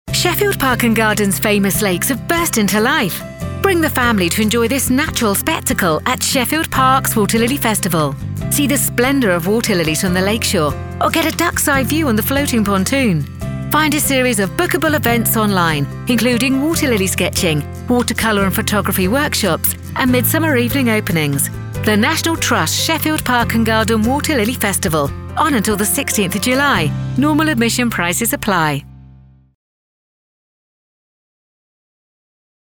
Meine Stimme wird als nahbar, voll, selbstsicher, sanft, selbstbewusst und vertrauenswürdig beschrieben.
Professionelle Gesangskabine mit kabelloser Tastatur und Maus.
Focsurite Scarlett 2i2, D2 Synco-Richtmikrofon und Twisted-Wave-Aufnahmesoftware.
Niedrig